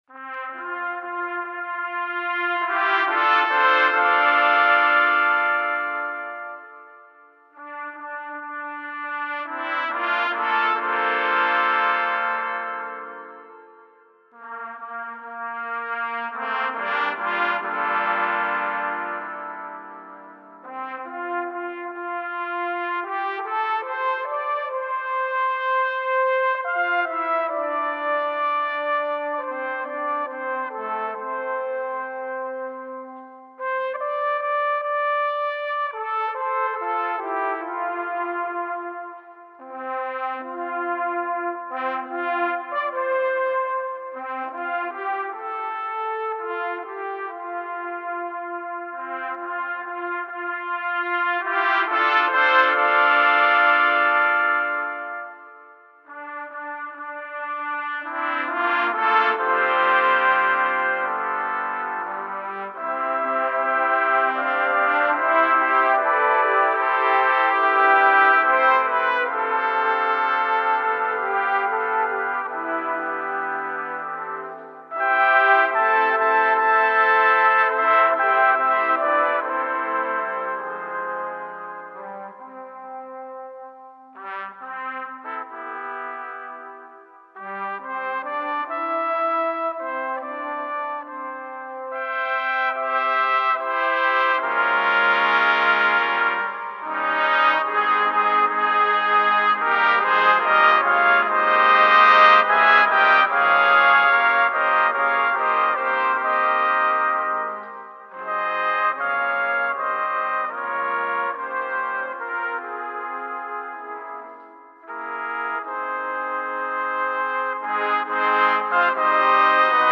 Number of Trumpets: 9
Key: F Major concert